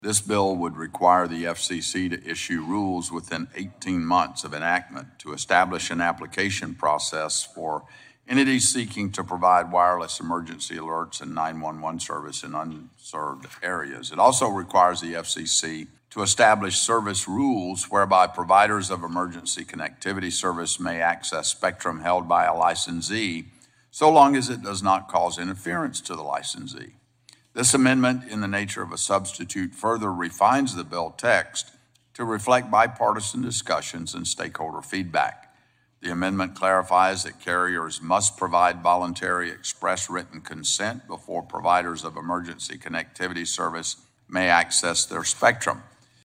Johnson explains the bill.